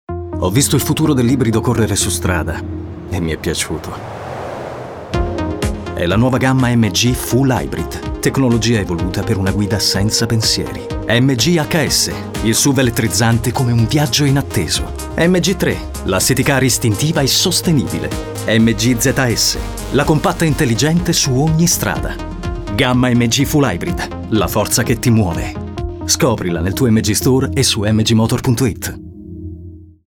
Demo
tv